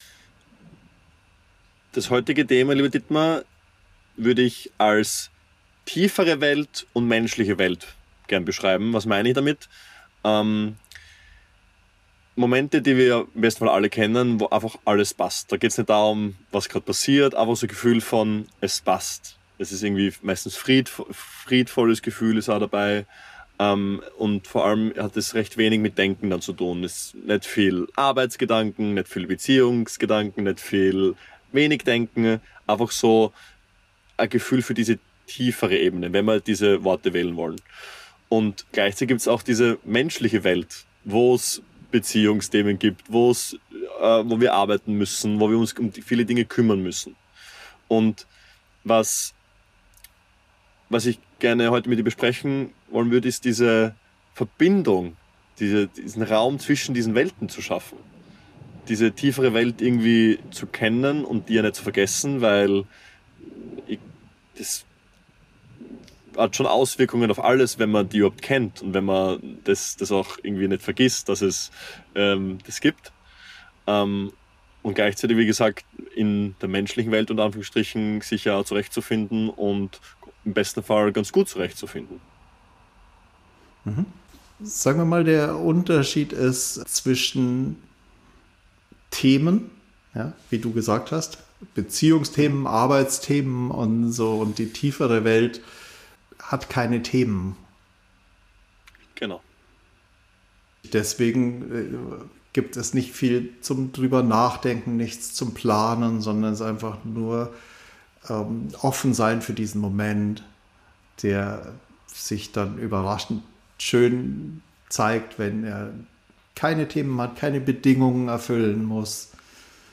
Wie kann ich in der Tiefe des Erlebens bleiben, wenn ich Alltagsdinge erledige? Re-Source – Gespräch 5 Ausgangspunkt ist ein einfacher Kontrast: Sonnenuntergang und ein spielender Hund – dann "Themen" wie das Formulieren einer Mail. Daraus entsteht die Frage: Verschwindet Tiefe wirklich, wenn sich die Aufmerksamkeit bewegt?